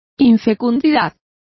Complete with pronunciation of the translation of infertility.